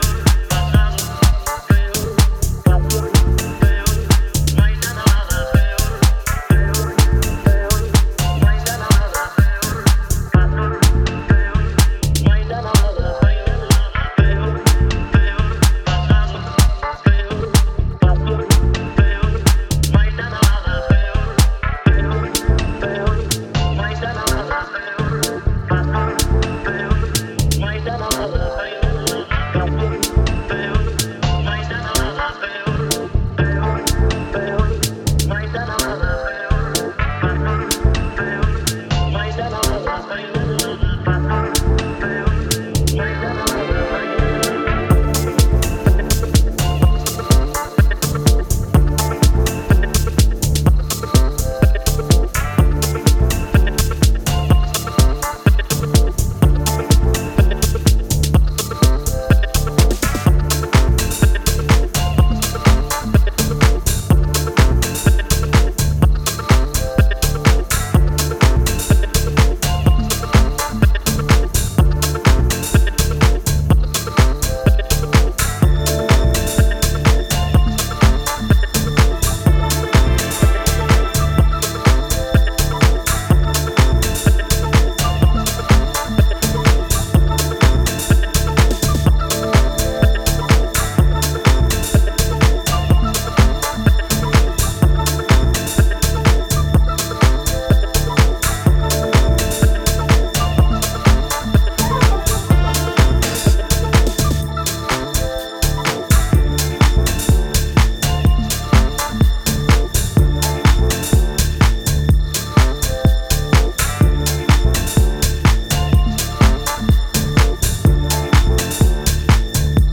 Styl: House, Techno